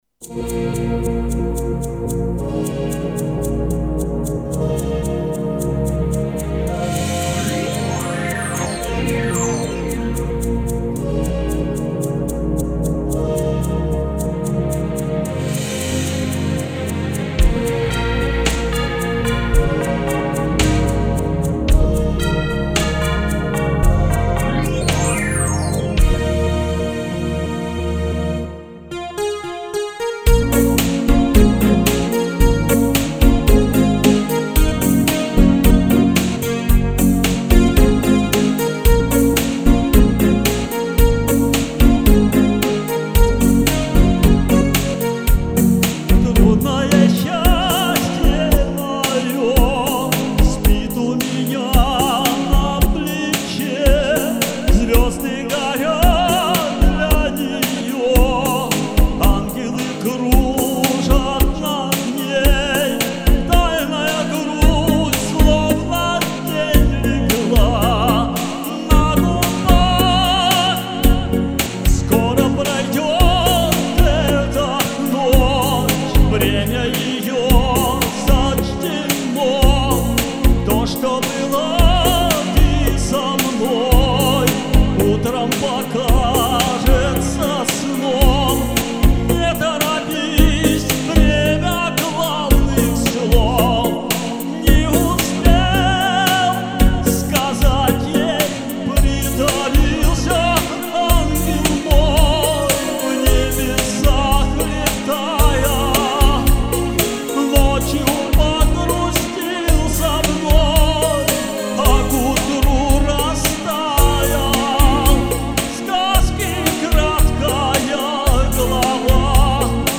немного не хватило свободы в вокале....Пардон-пардон